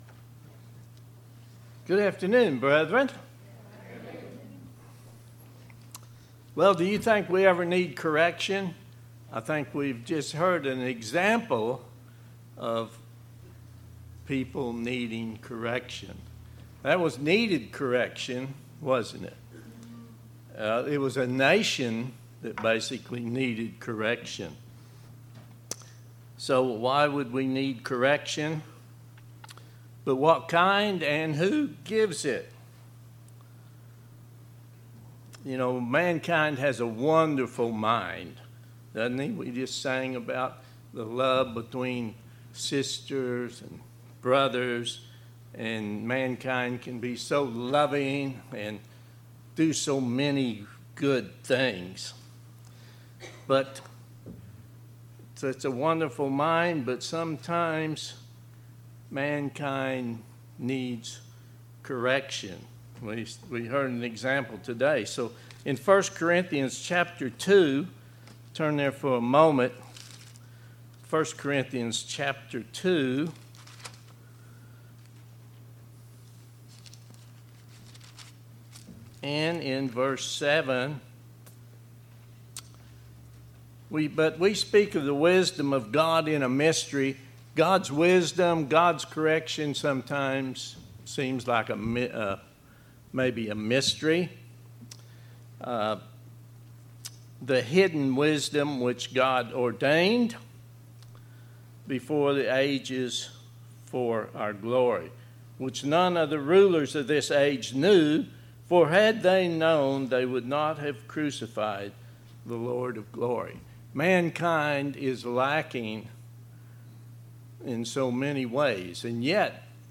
In this sermon we will discuss the need for correction. Correction given out of love, receiving correction, self correction, and giving correction. Mankind has great physical accomplishments, but has also caused destruction and death.